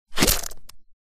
attackblob.ogg